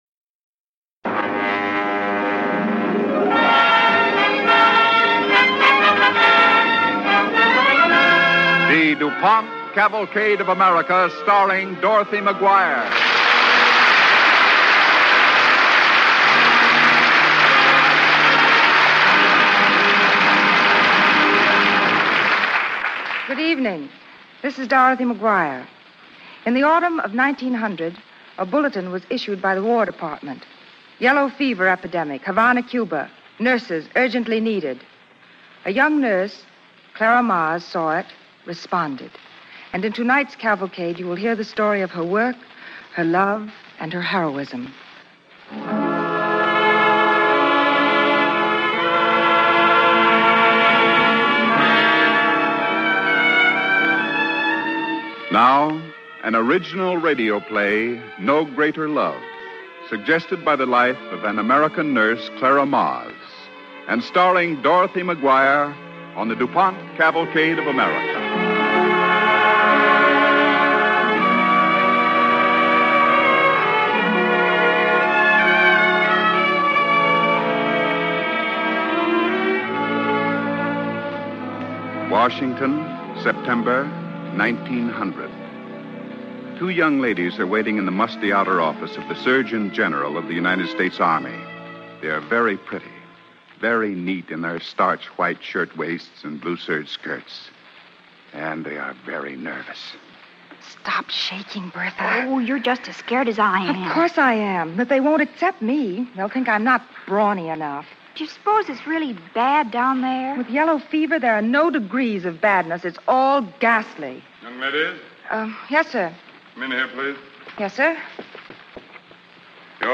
Cavalcade of America Radio Program